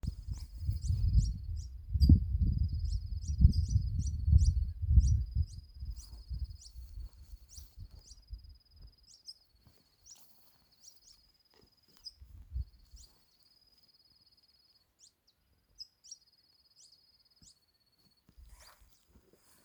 Canastero Coludo (Asthenes pyrrholeuca)
Nombre en inglés: Sharp-billed Canastero
Localización detallada: Camino a El Duraznal
Condición: Silvestre
Certeza: Fotografiada, Vocalización Grabada
Canastero-coludo_1.mp3